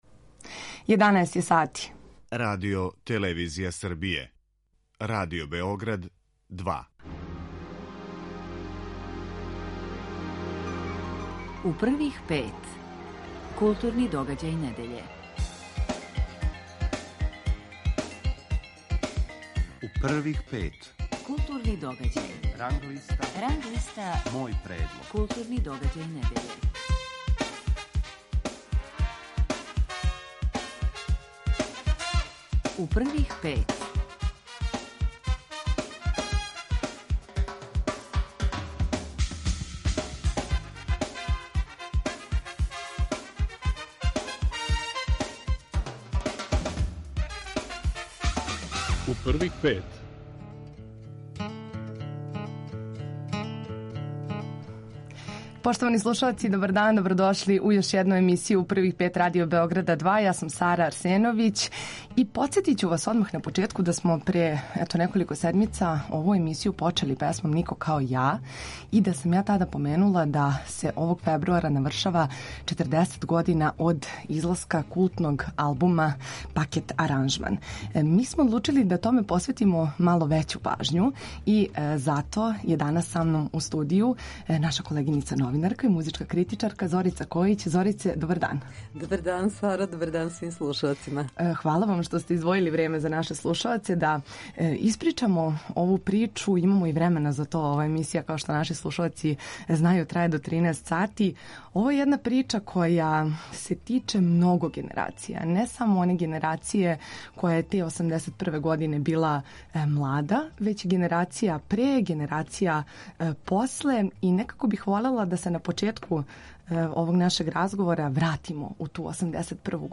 У емисији нас очекује и укључење са доделе награде Фондације Тања Петровић која се додељује за изузетан допринос афирмисању културе и уметности у медијима.